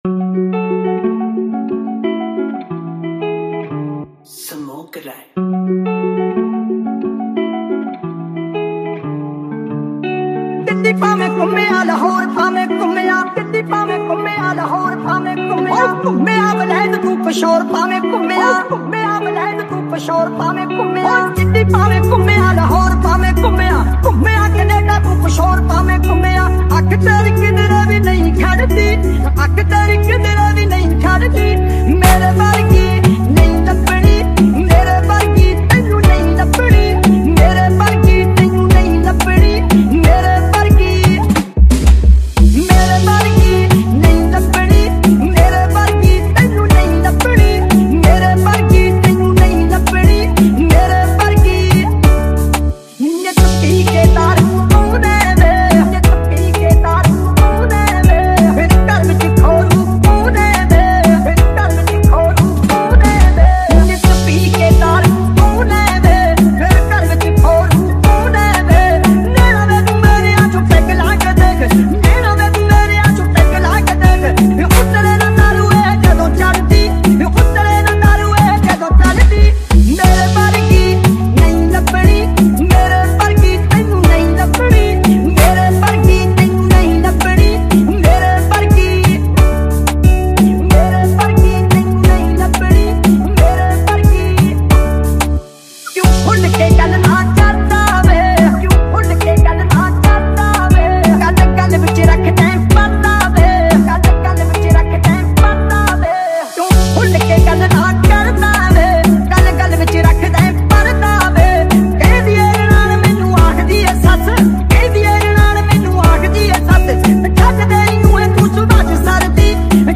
Releted Files Of DJ Remix